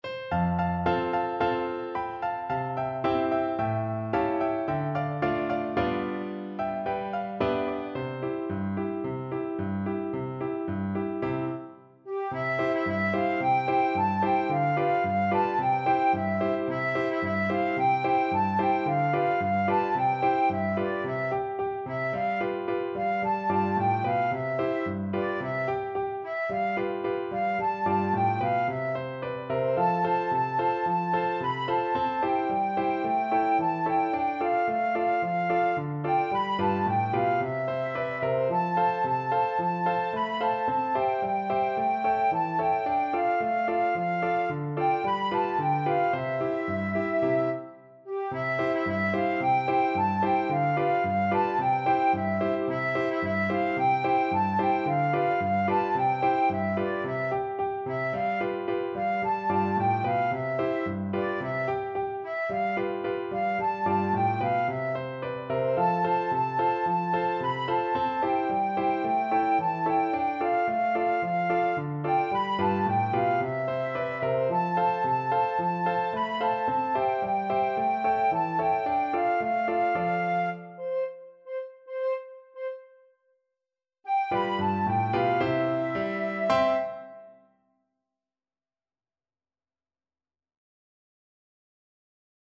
Flute
Traditional Music of unknown author.
C major (Sounding Pitch) (View more C major Music for Flute )
Allegro =c.110 (View more music marked Allegro)
2/4 (View more 2/4 Music)
G5-C7
Classical (View more Classical Flute Music)